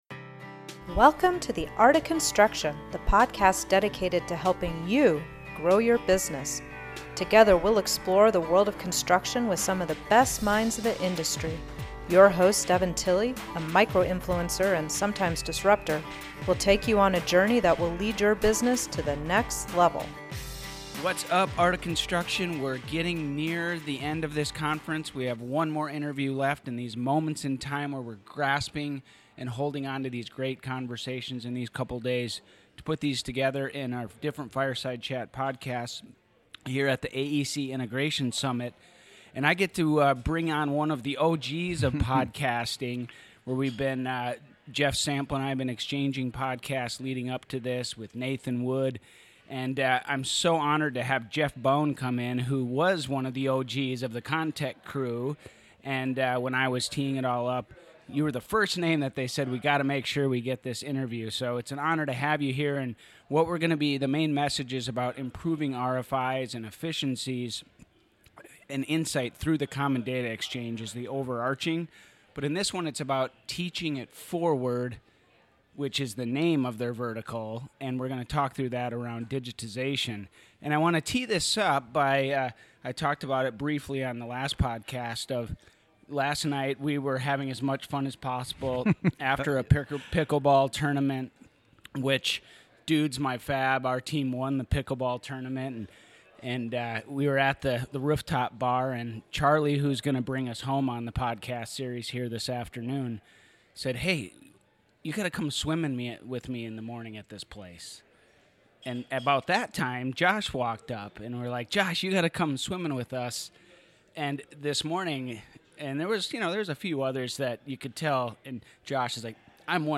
This is episode 3 of 4 for a Deep Dive Series recorded at the AEC Summit in Austin, Texas in April 2024! If you hear any background noise, that's because this episode was recorded LIVE at the AEC Summit!